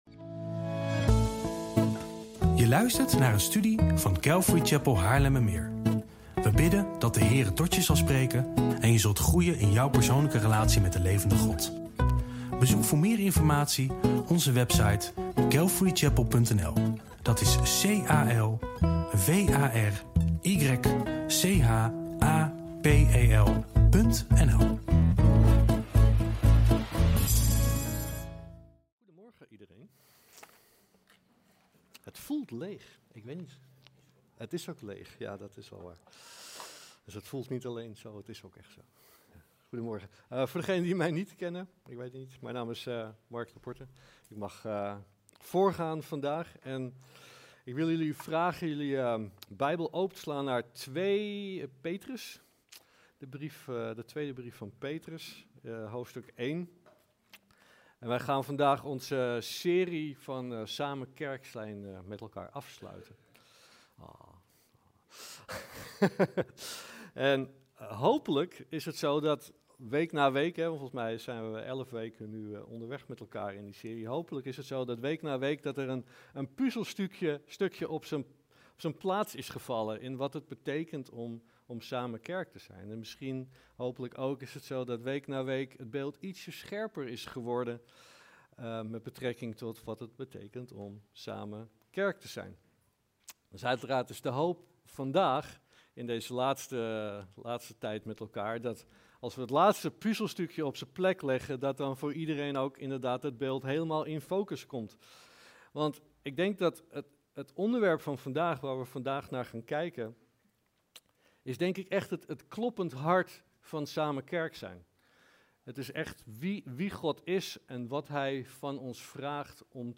Preek-30-3.mp3